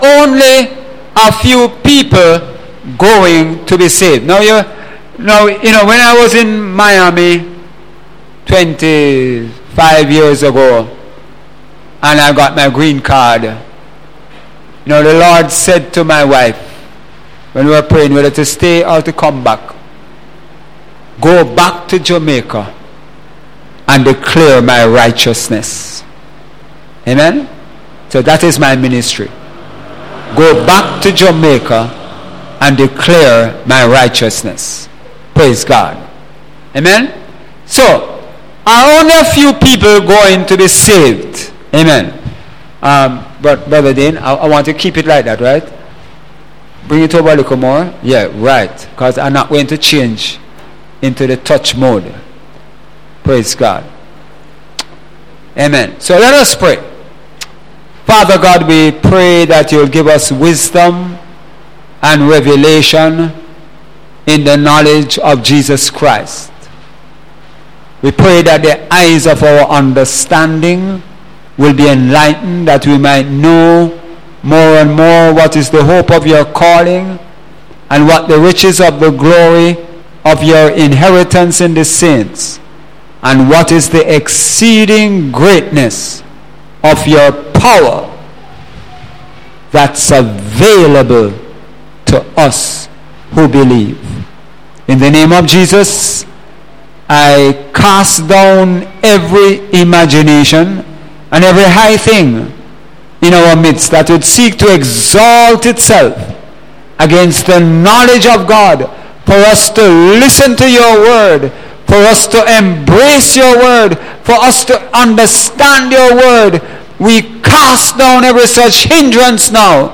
Sunday Sermon – Are only a few people to be saved?